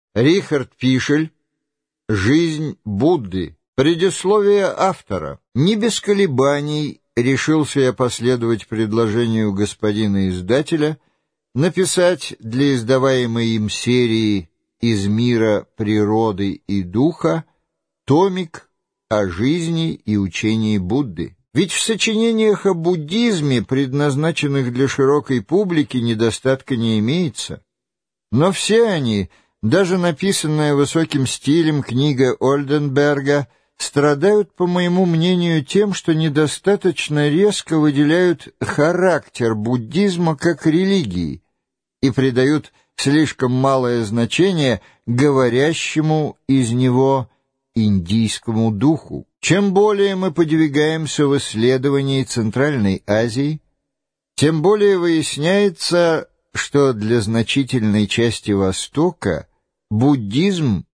Аудиокнига Будда, его жизнь и учение | Библиотека аудиокниг